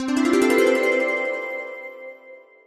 disappear.ogg.mp3